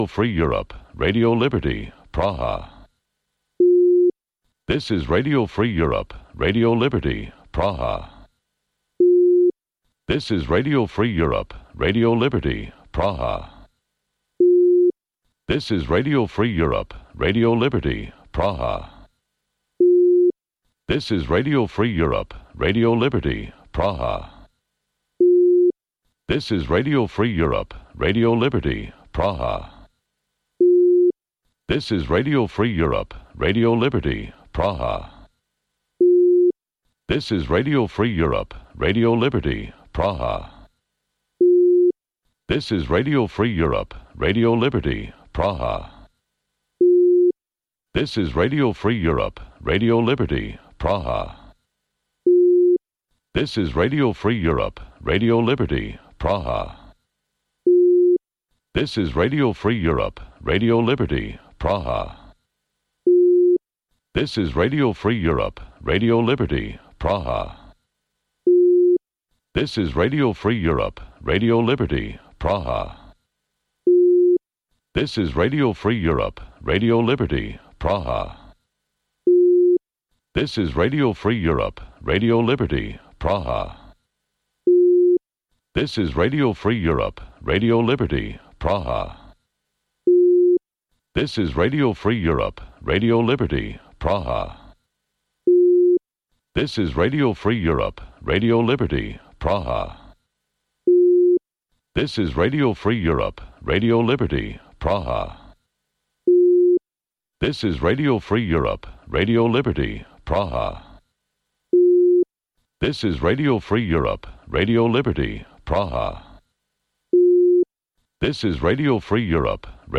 Преглед на вестите и актуелностите од Македонија и светот, како и локални теми од земјата од студиото во Прага.